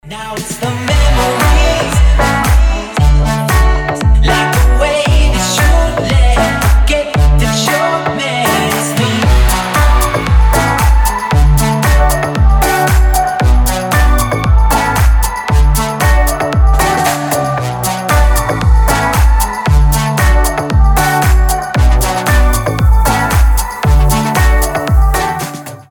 • Качество: 320, Stereo
deep house
Club House
Красивая хаус-музыка